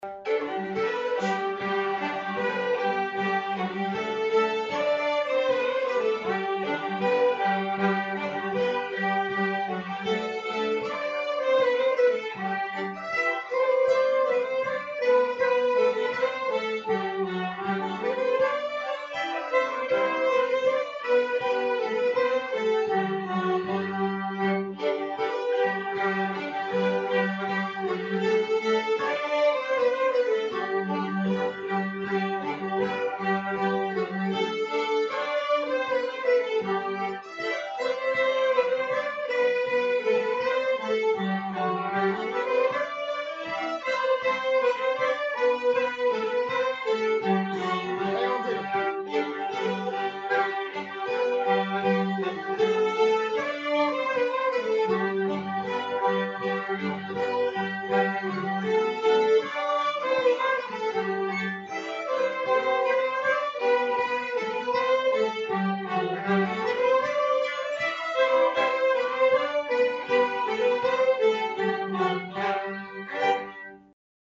Kadrilj | Lustspel
Spelas utan repris på B-delen till dansen Landskronakadrilj.